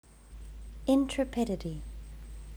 (Say it "IN truh PID ih tee." Hear it here .)
intrepidity.wav